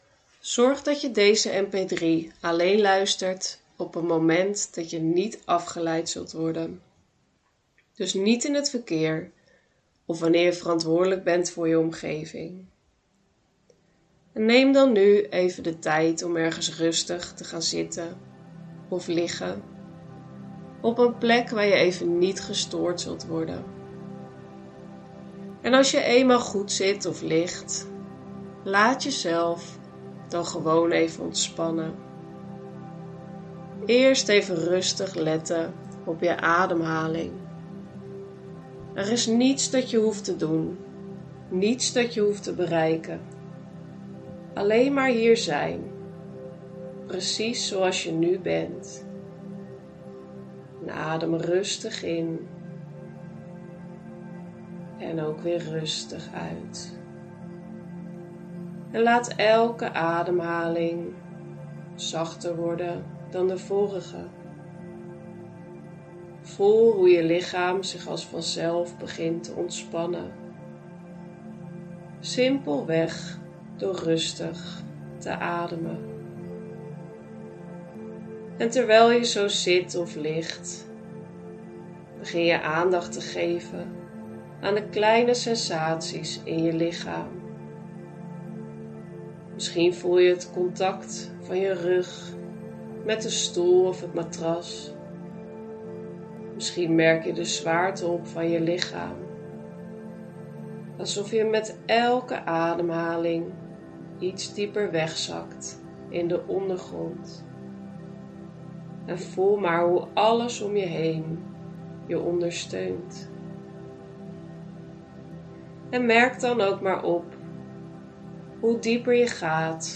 Download mijn gratis zelfhypnose MP3.